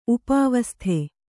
♪ upāvasthe